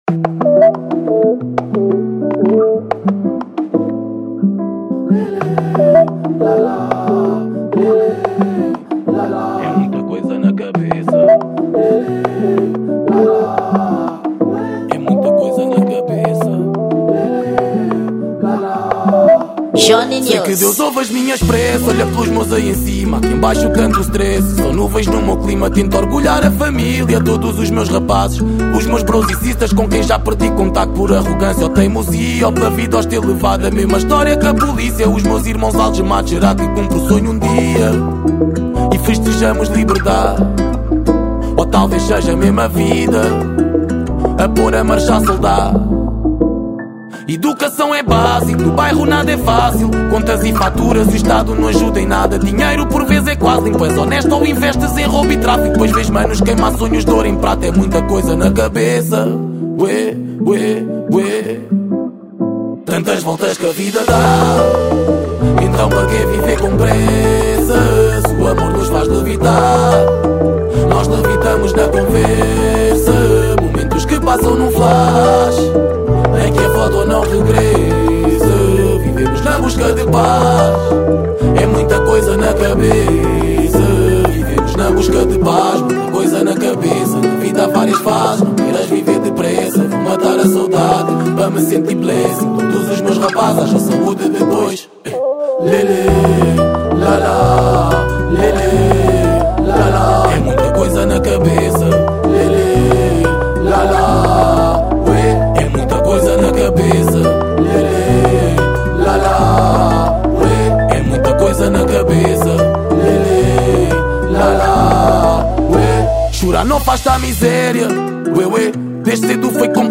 Gênero: Acústico